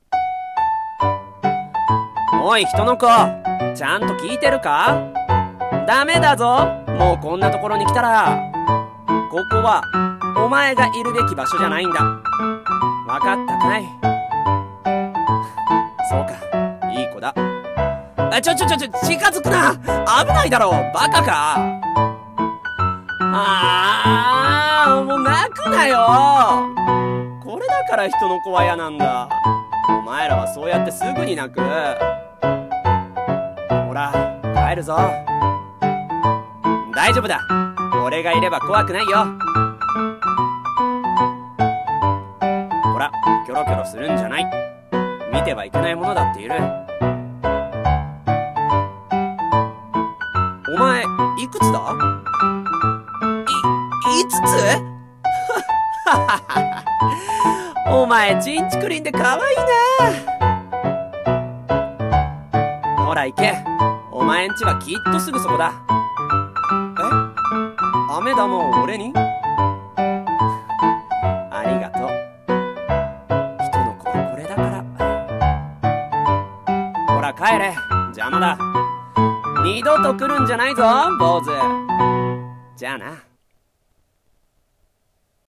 声劇】飴玉